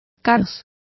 Complete with pronunciation of the translation of pandemoniums.